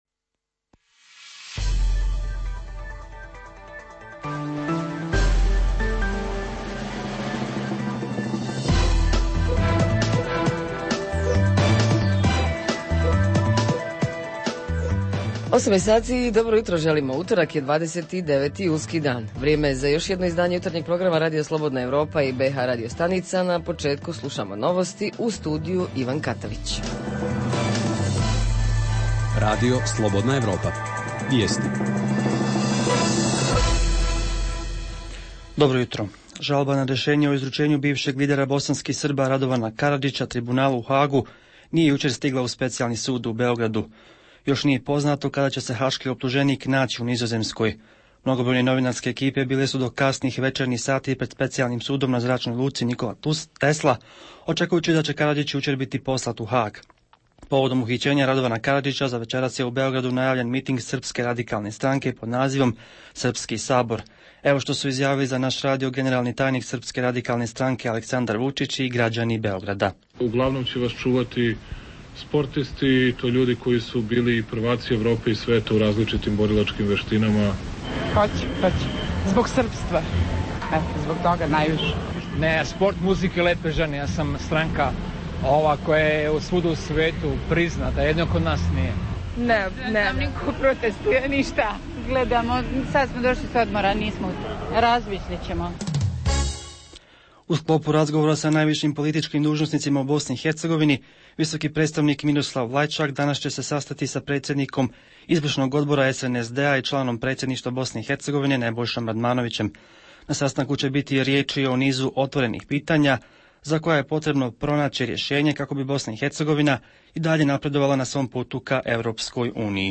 Jutarnji program za BiH koji se emituje uživo. Govorimo o bh. dijaspori - šta očekuje od svoje države, a šta joj može ponuditi.
Redovni sadržaji jutarnjeg programa za BiH su i vijesti i muzika.